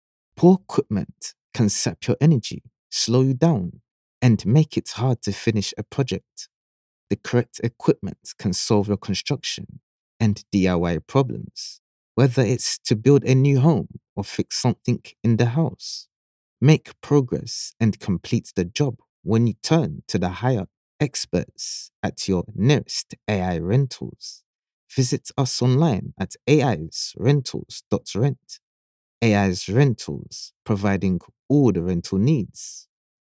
British Radio & TV Commercial Voice Overs Artists
English (Caribbean)
Adult (30-50) | Yng Adult (18-29)